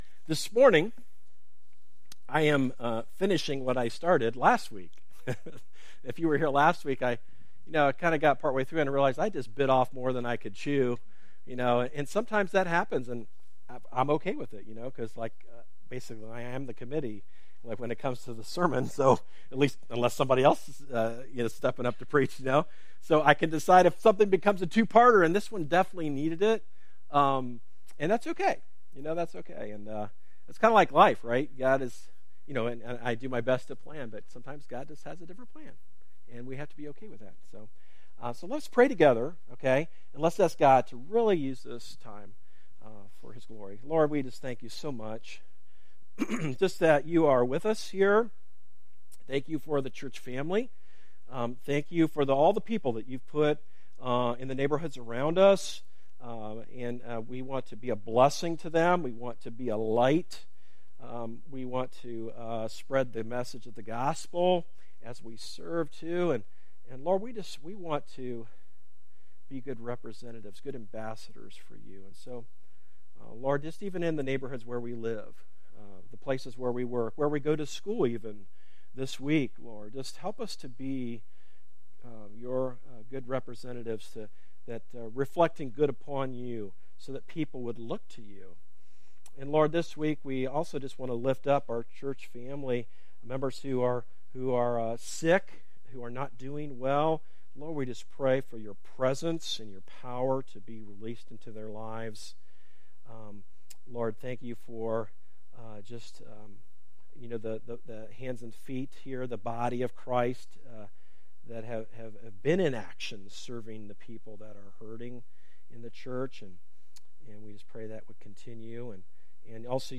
A message from the series "1 Kings Series."